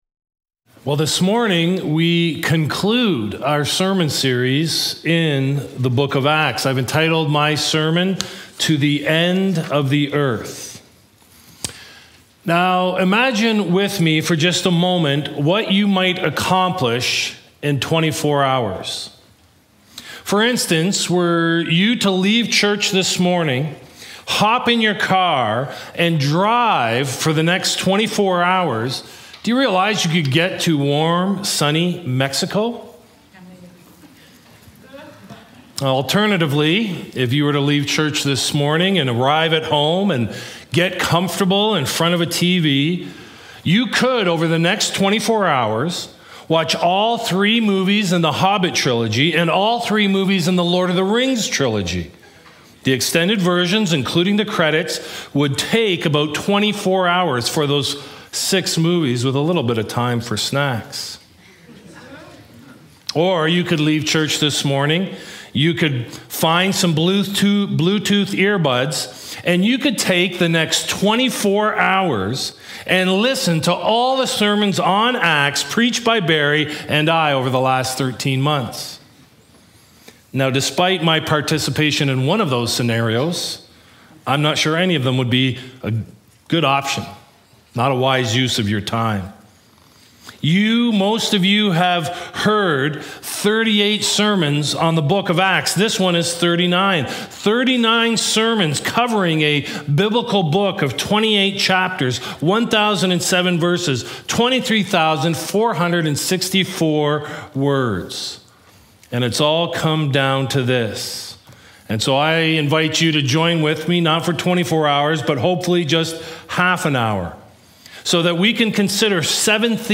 Sermon Archives To the End of the Earth